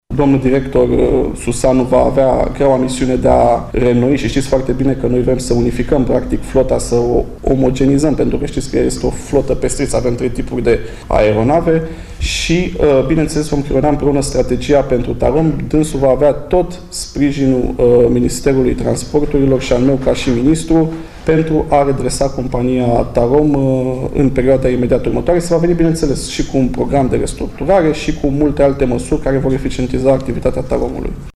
Noua conducere a TAROM va anula mai multe curse neperformante, unde gradul de încărcare era de 25%, iar avioanele zburau cu doar zece pasageri la bord, a anunţat, azi, ministrul Transporturilor, Răzvan Cuc, într-o conferinţă de presă.
a precizat ministrul Cuc.